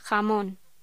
Locución: Jamón
voz